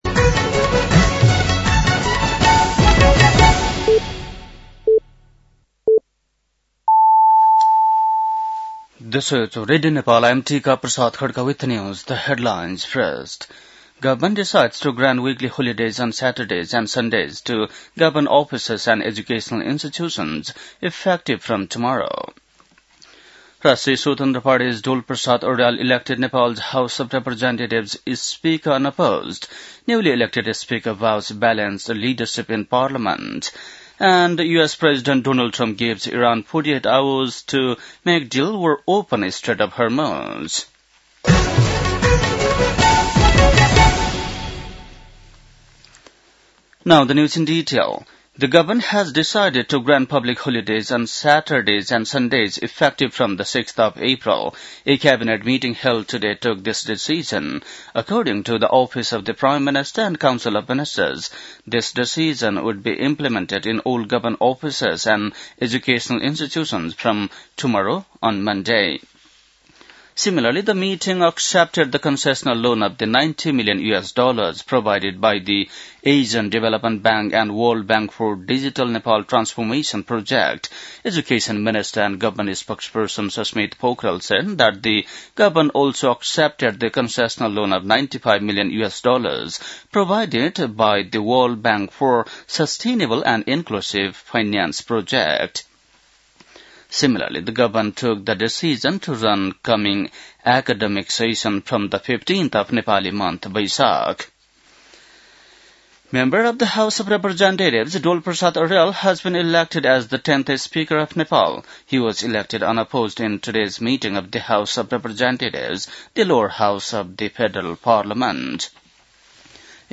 बेलुकी ८ बजेको अङ्ग्रेजी समाचार : २२ चैत , २०८२
8.-pm-english-news.mp3